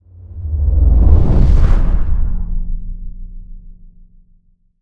cinematic_deep_bass_pass_whoosh_01.wav